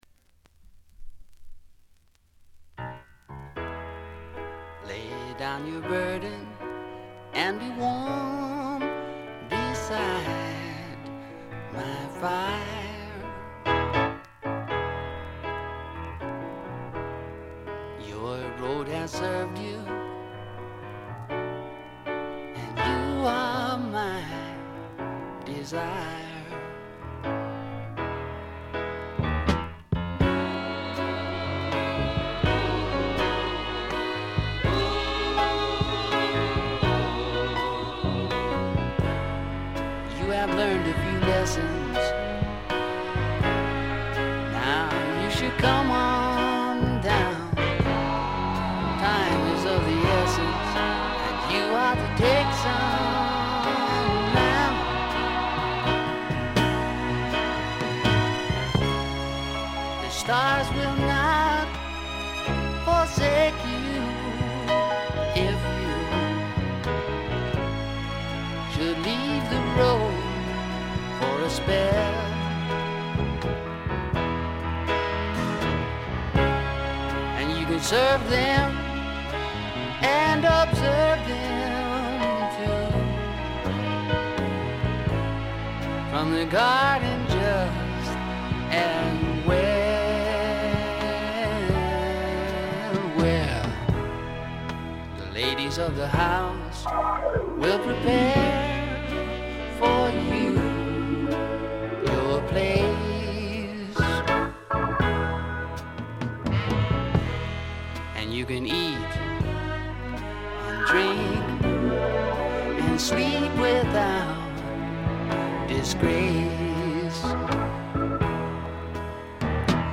ごくわずかなノイズ感のみ。
独特のしゃがれた渋いヴォーカルで、スワンプ本線からメローグルーヴ系までをこなします。
試聴曲は現品からの取り込み音源です。